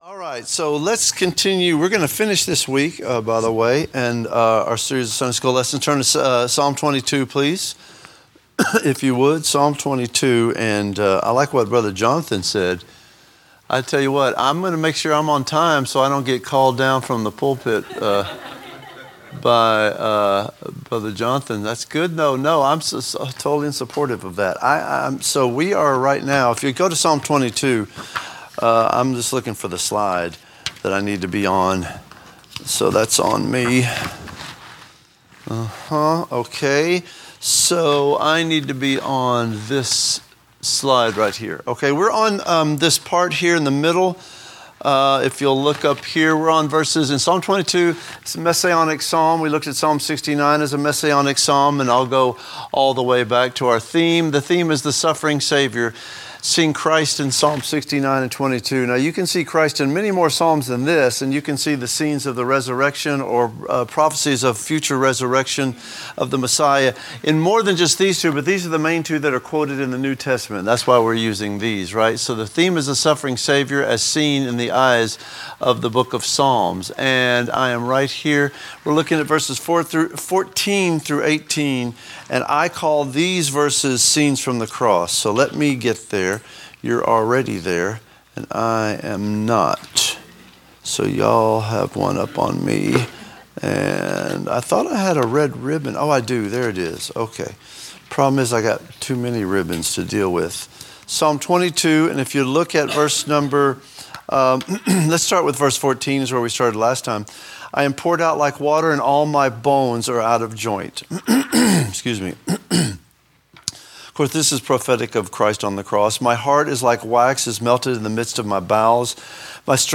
A message from the series "The Suffering Saviour."